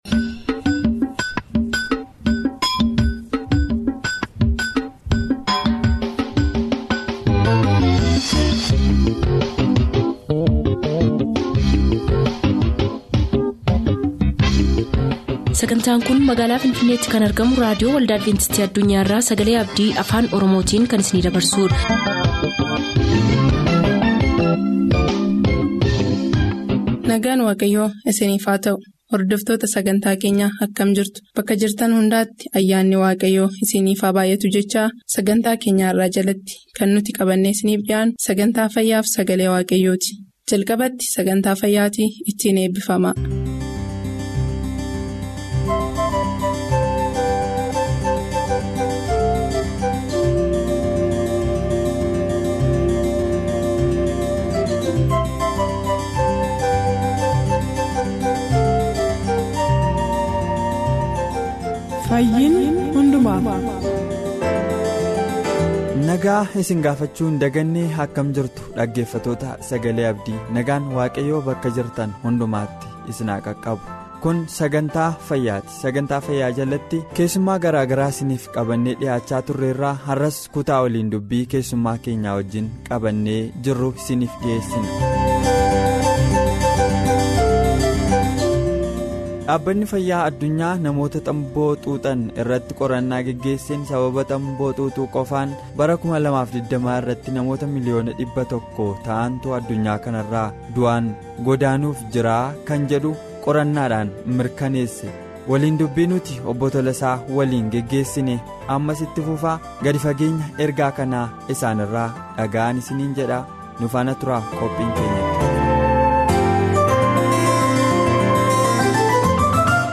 Daily Oromifaa radio programs from Adventist World Radio for Ethiopia, Kenya & Somalia